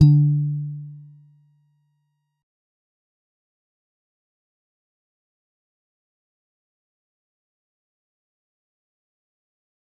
G_Musicbox-D3-mf.wav